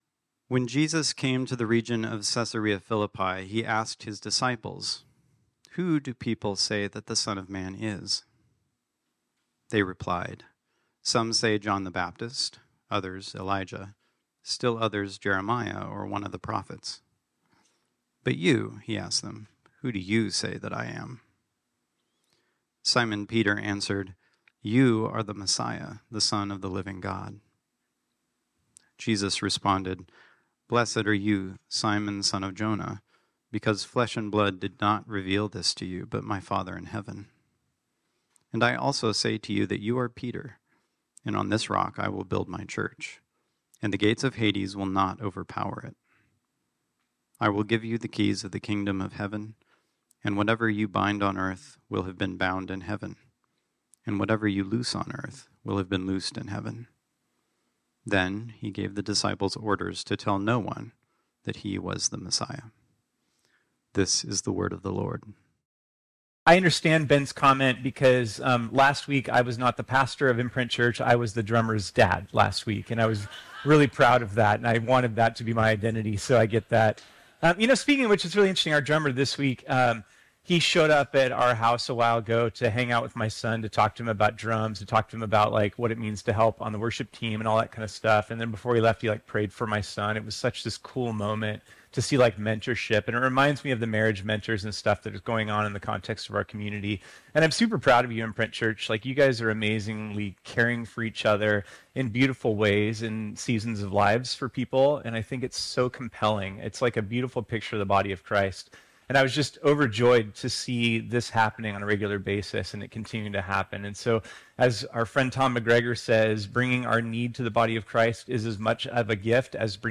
This sermon was originally preached on Sunday, July 21, 2024.